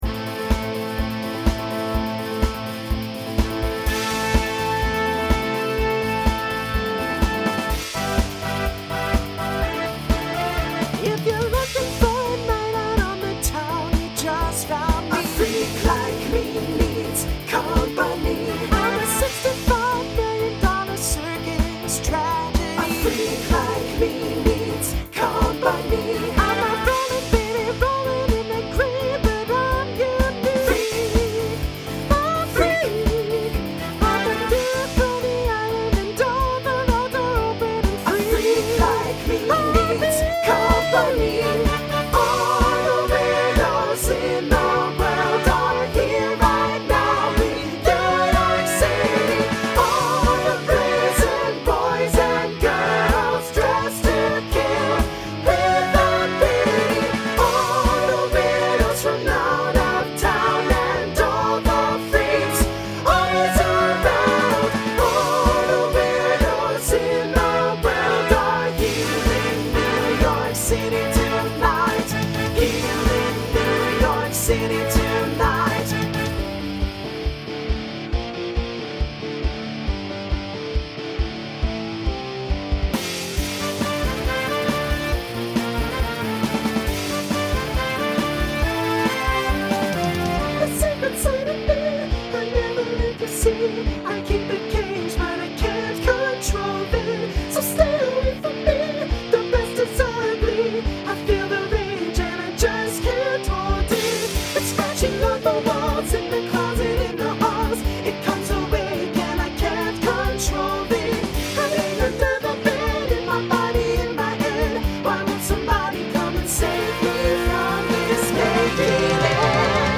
New SATB voicing for 2022.